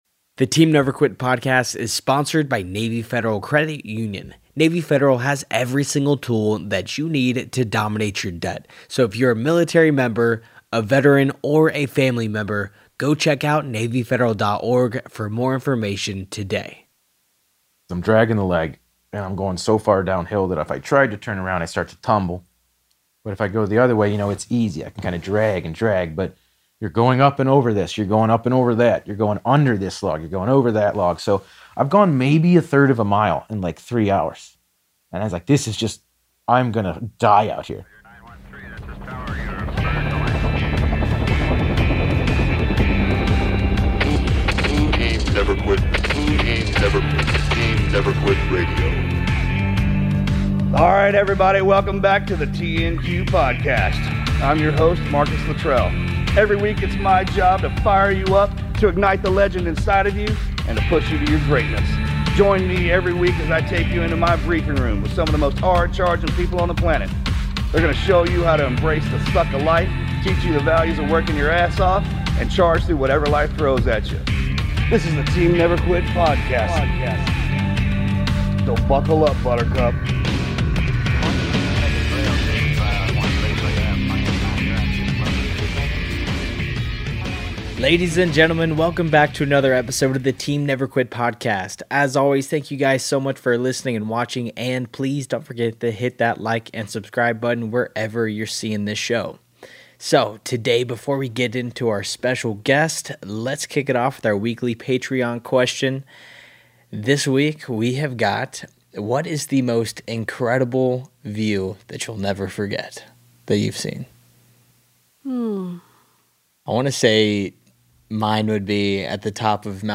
Whether you hunt, hike, or just love hearing how ordinary people survive extraordinary situations, this conversation will stay with you.